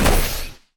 poly_shoot_missile02.wav